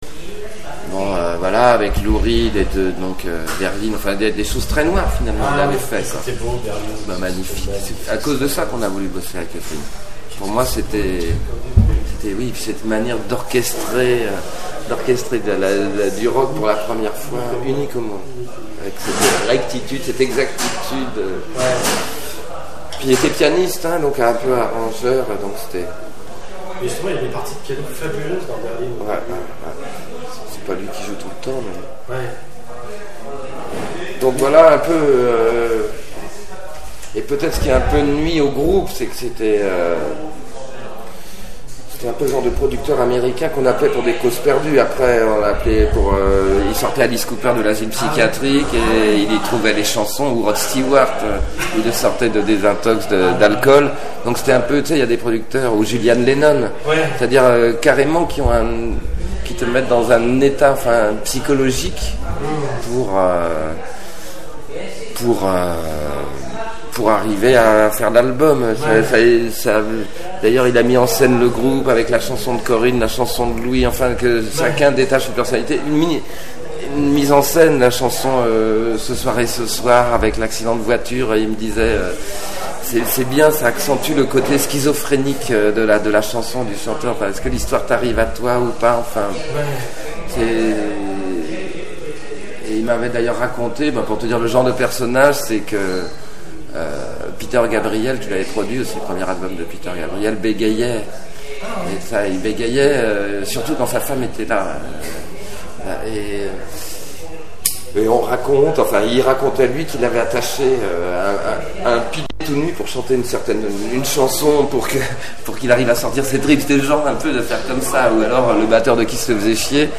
Voici quelques extraits d'interviews de Jean-Louis Aubert menées pour la biographie de Téléphone...